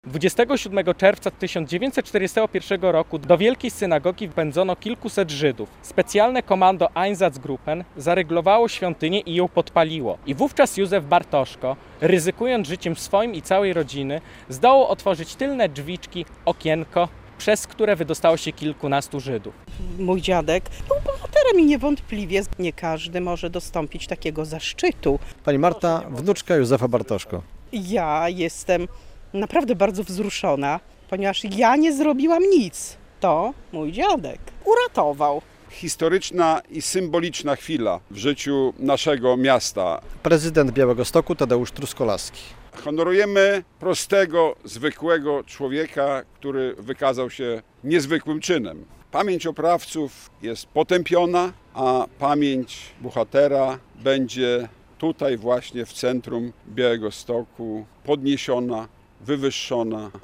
To upamiętnianie prostego, zwykłego człowieka, który wykazał się niezwykłym czynem - powiedział prezydent Białegostoku w tracie uroczystości odsłonięcia tablicy z nazwą skweru w Białymstoku.
W Białymstoku został upamiętniony Józef Bartoszko - relacja